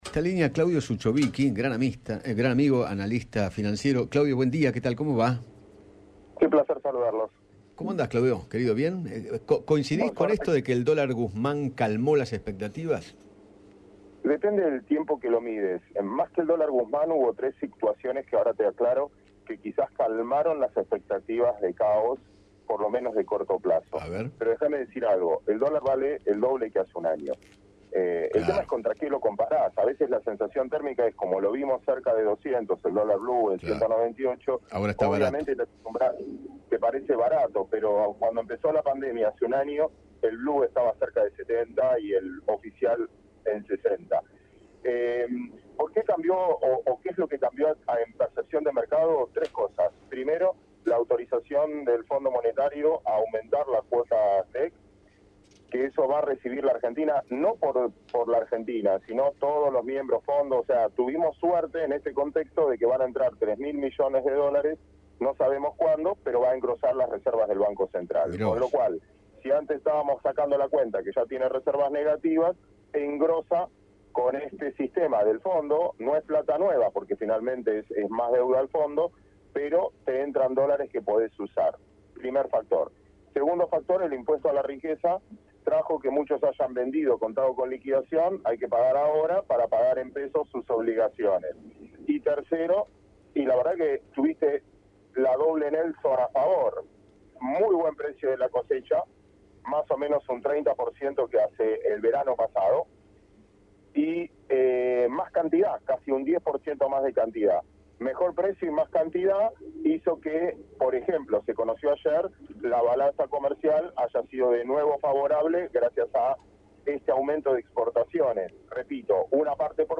El analista financiero, Claudio Zuchovicki, conversó con Eduardo Feinmann sobre el presente económico, las expectativas y el freno en la suba de la cotización del dólar.